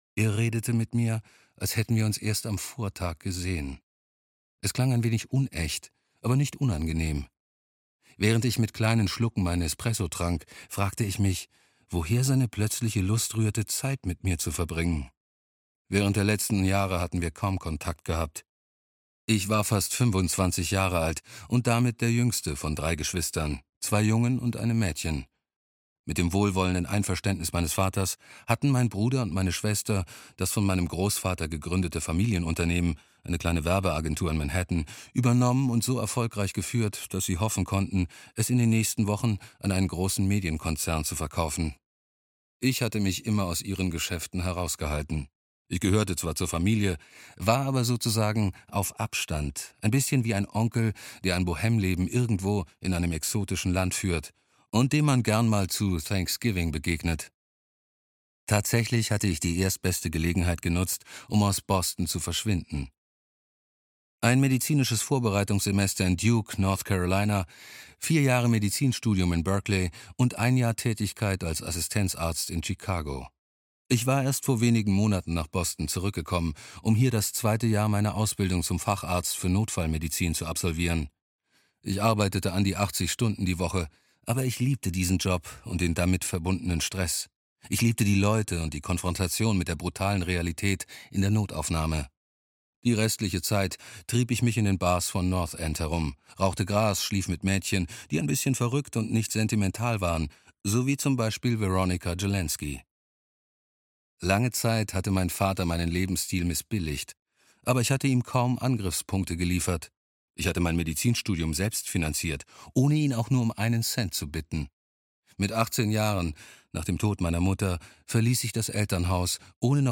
Vierundzwanzig Stunden - Guillaume Musso - Hörbuch